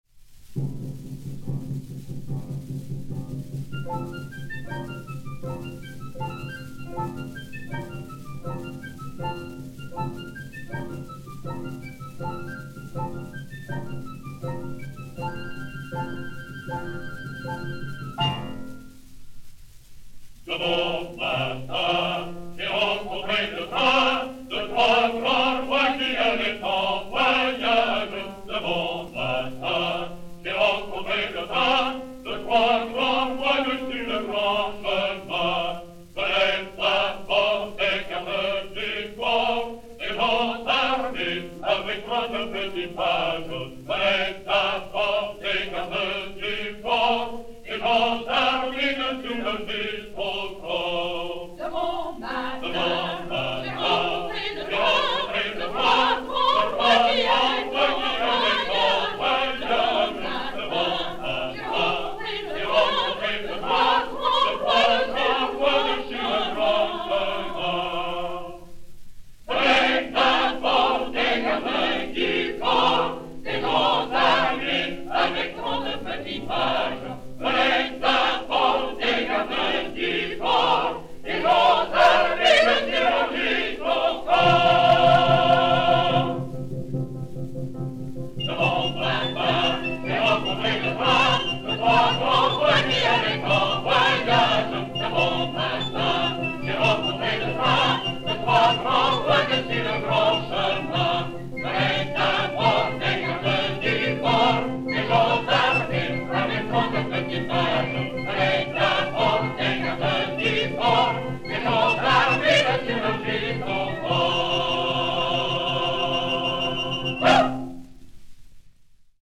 Chœurs du Théâtre National de l'Odéon
Orchestre Symphonique